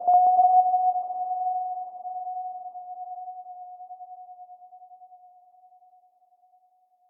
b_sonar_v100l2o6fp.ogg